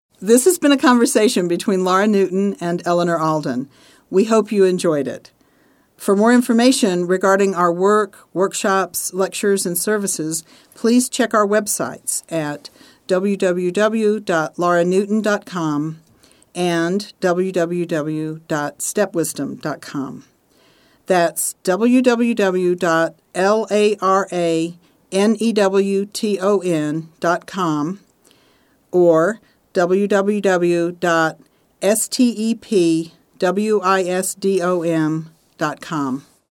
Reading and Discussion of two fairytales and their relationships to brother-sister psychology and also to questions of stepparenting: 1) Hansel and Gretel - Sibling Cooperation, Mutuality and Authority 2) The Goose Girl at the Well: The Jungian theme of the dual mother and the fairy tale's image of an "ideal" StepMother.